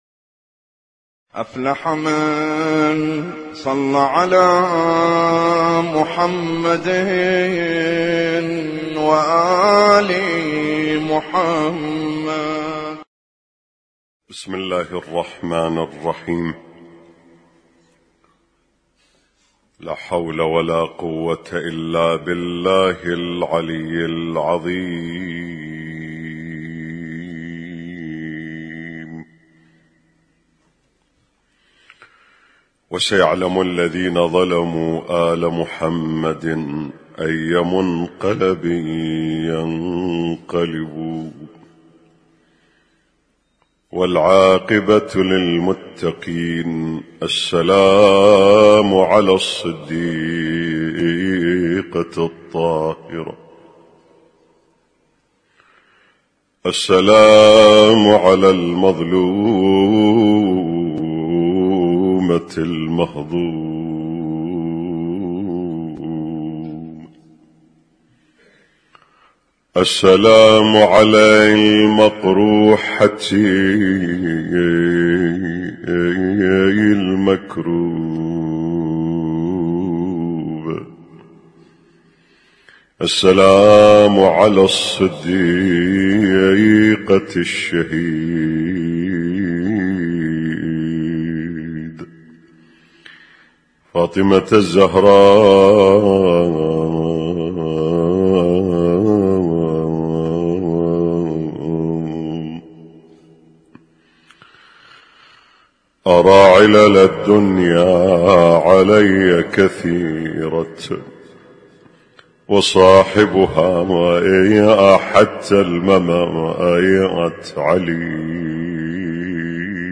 Husainyt Alnoor Rumaithiya Kuwait